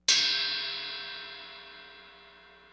cymb.mp3